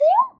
gui-out.ogg